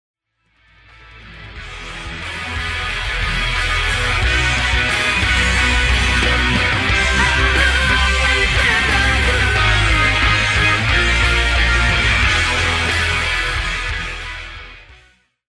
isolated background vocals